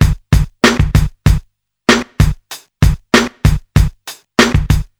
• 96 Bpm Rap Drum Beat A Key.wav
Free breakbeat - kick tuned to the A note. Loudest frequency: 1051Hz
96-bpm-rap-drum-beat-a-key-Yv9.wav